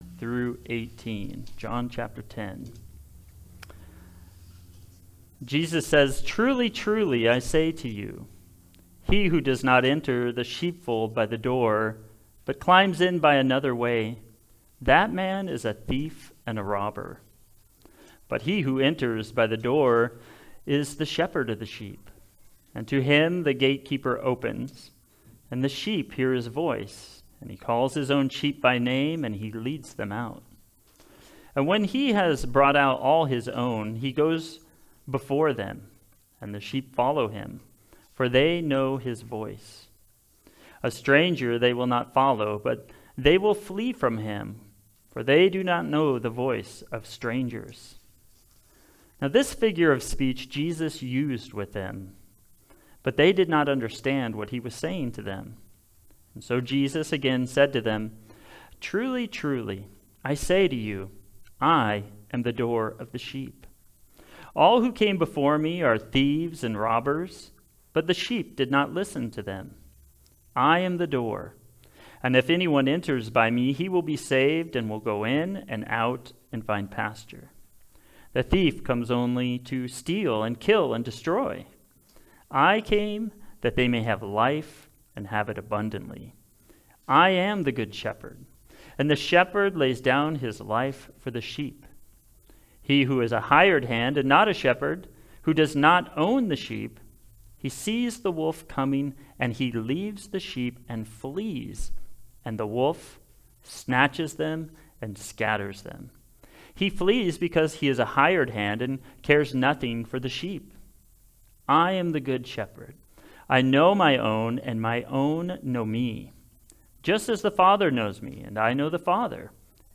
Preaching
Passage: John 10:1-18 Service Type: Sunday Service